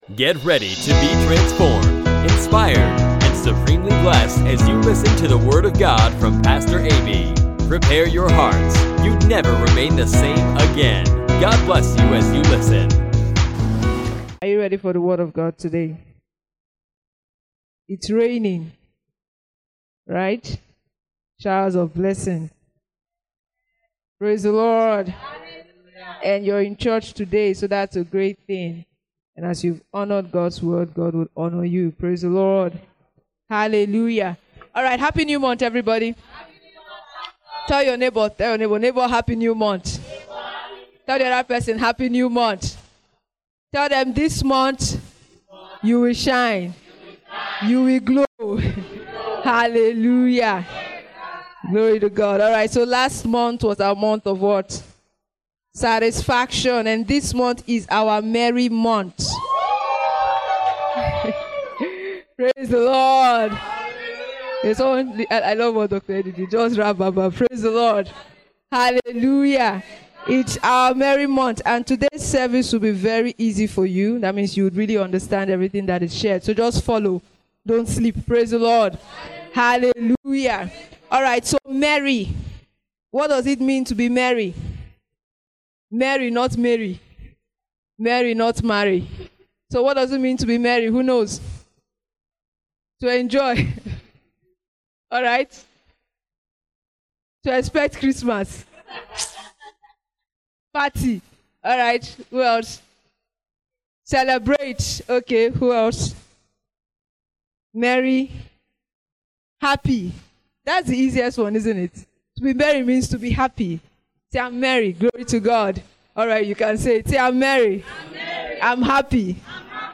Pastor teaches on Merry Man